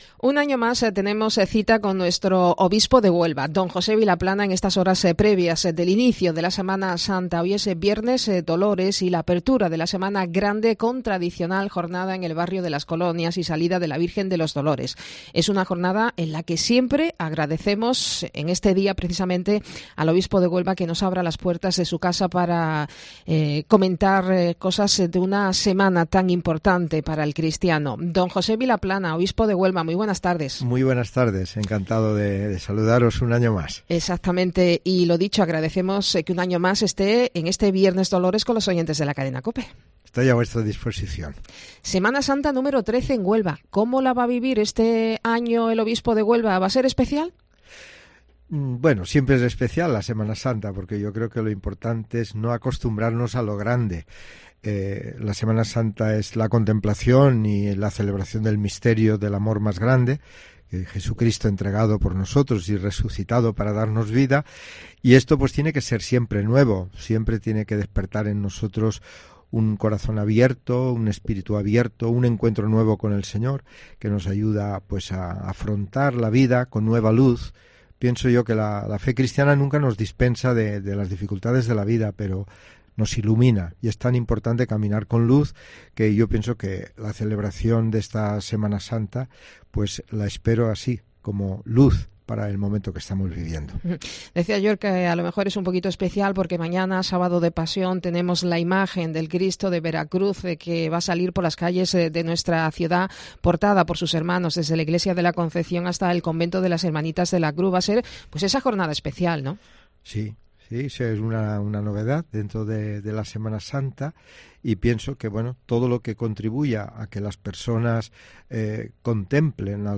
D. José Vilaplana Blasco, Obispo de Huelva